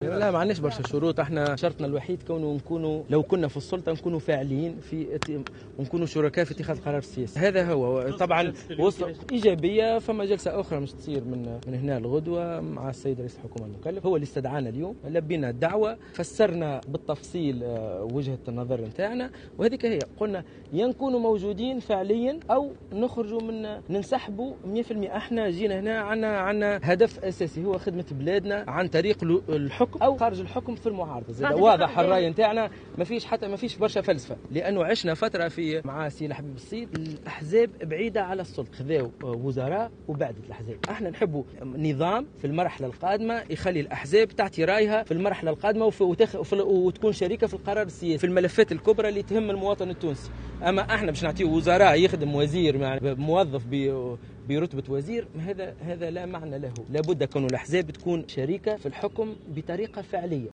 وقال سليم الرياحي في تصريح لمراسل الجوهرة أف أم " إما أن نكون يكون دورنا فاعلا أو سننسحب نهائيا.. وهدفنا الأساسي هو خدمة البلاد سواء في الحكم أو في المعارضة.. " حسب قوله.